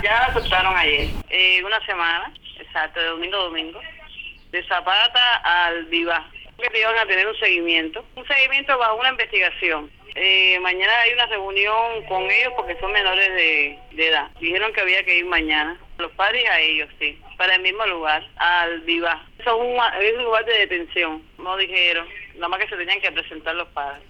La madre de la joven habló con Radio Martí